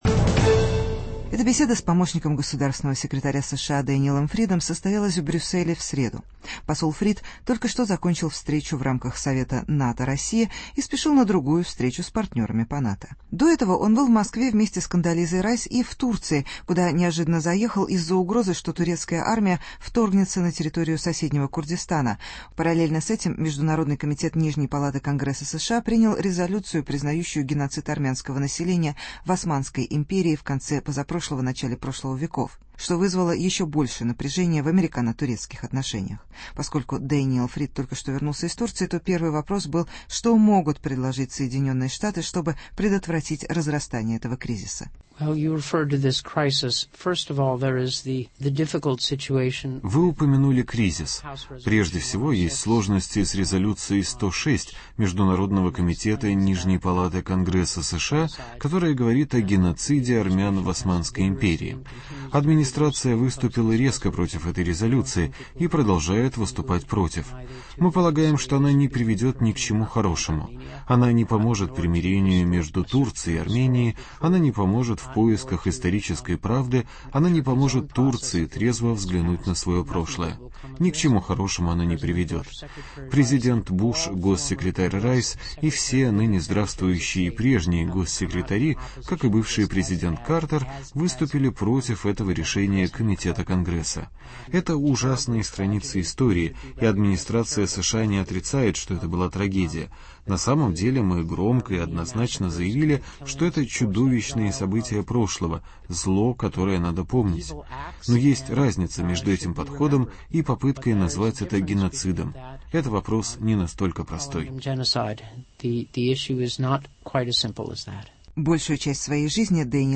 Интервью с Дэниэлом Фридом, помощником госсекретаря США